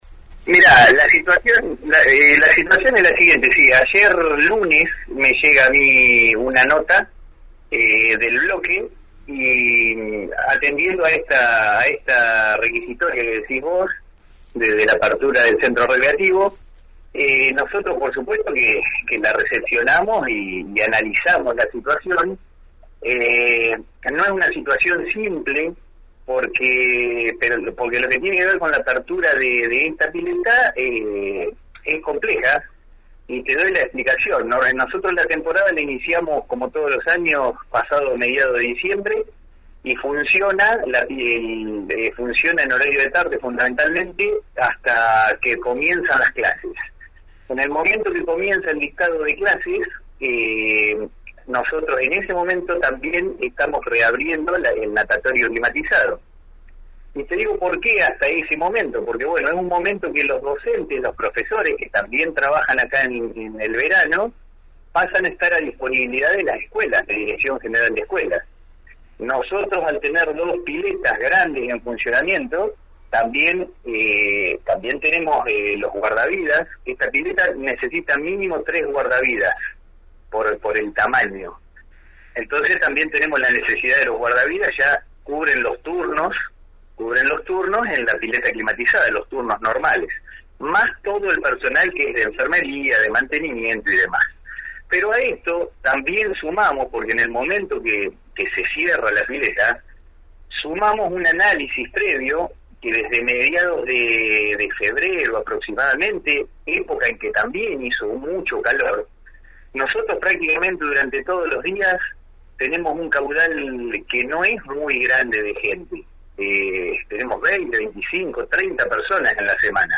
El secretario de deportes municipal, Fernando Muñiz, se refirió este martes en la 91.5 al pedido de los concejales del Bloque Adelante-Juntos de reabrir la pileta del Centro Recreativo «Néstor Kirchner» debido a la ola de calor.